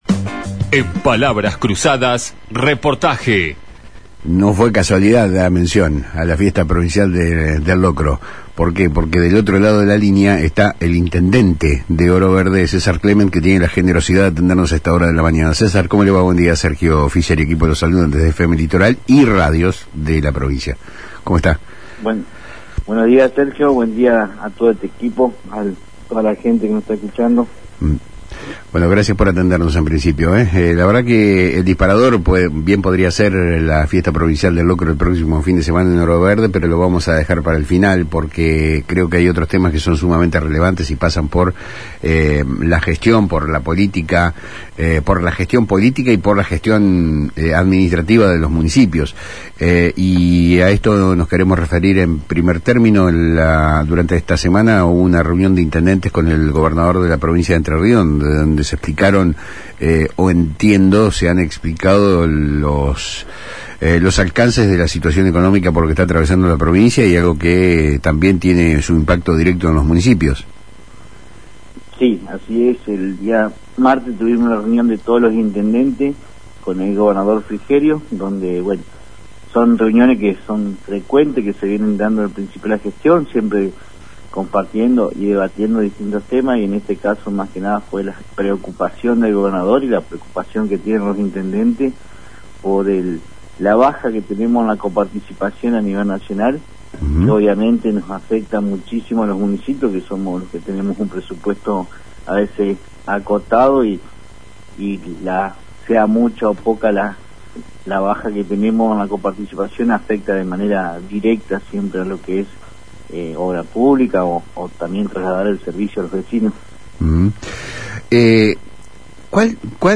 El intendente César Clement habló en FM Litoral sobre la situación económica que atraviesan los municipios, las gestiones para sostener los servicios esenciales y cómo la Fiesta Provincial del Locro se convierte en una herramienta de solidaridad y trabajo comunitario.